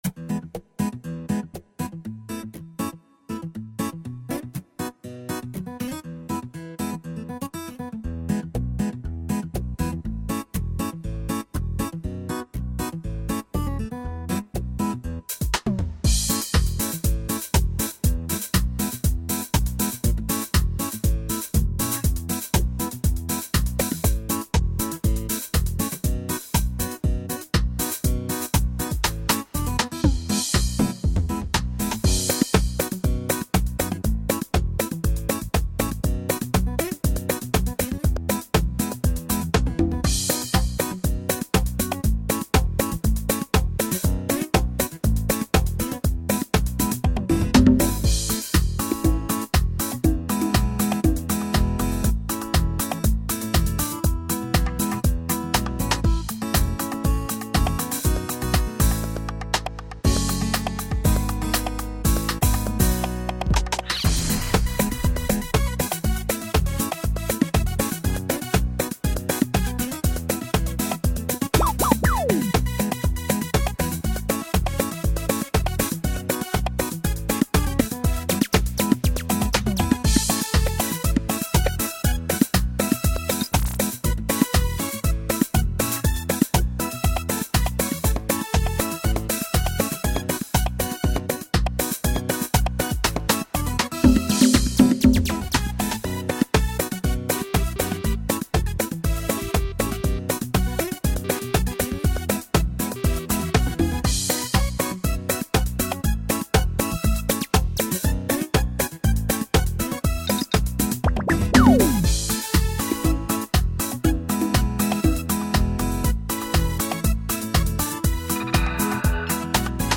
I used my motif rack ES with composing this song.